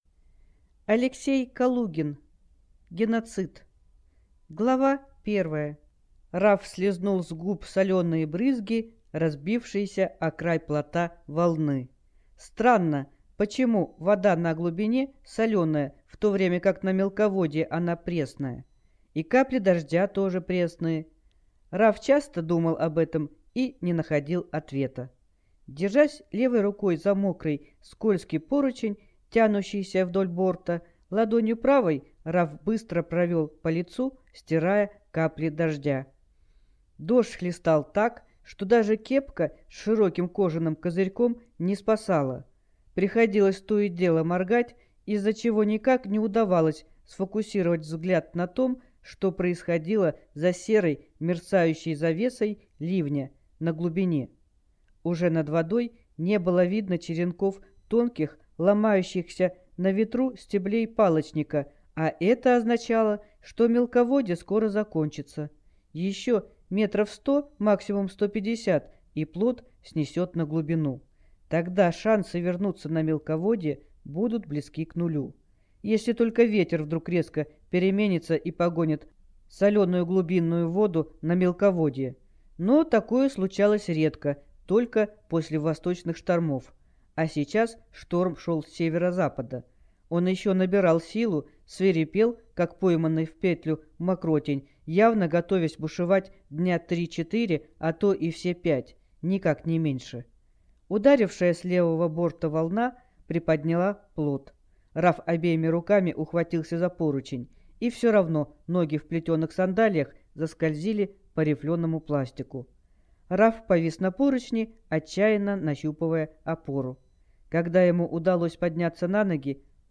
Аудиокнига Геноцид | Библиотека аудиокниг